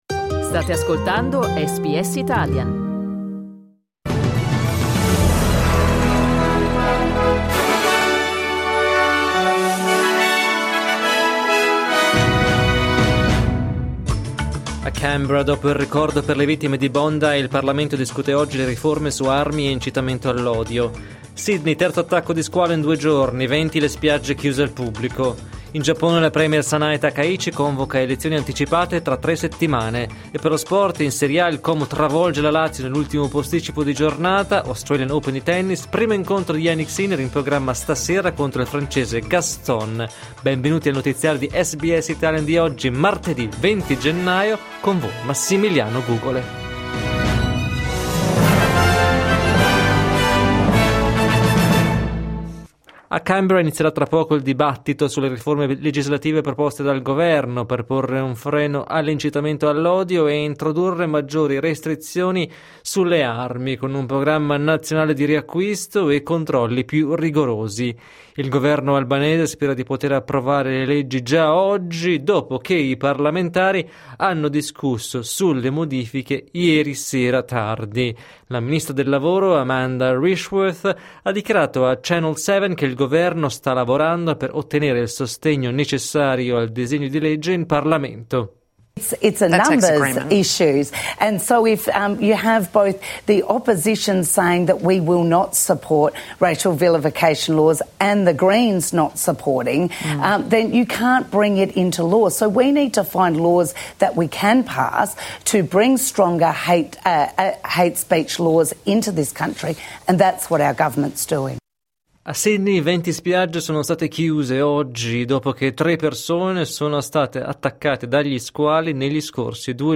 Giornale radio martedì 20 gennaio 2026
Il notiziario di SBS in italiano.